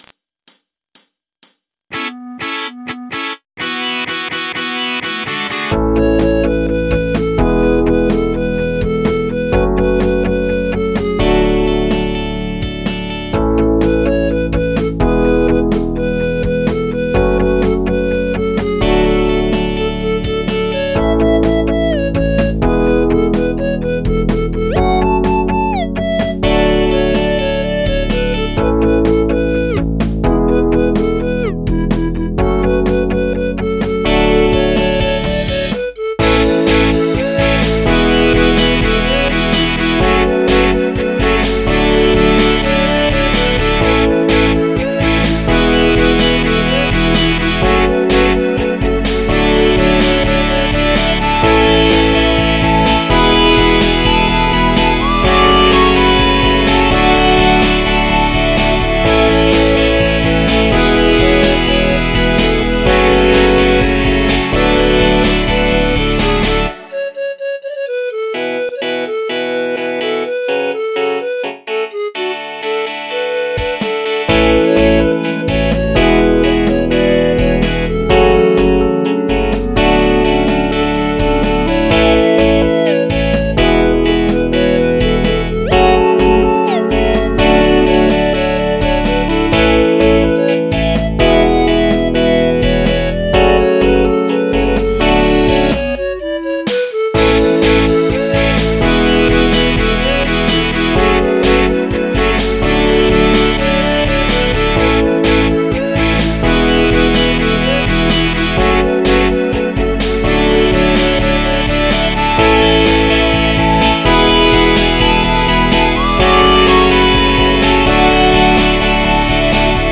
Karaoke Tracks
MIDI 66.48 KB MP3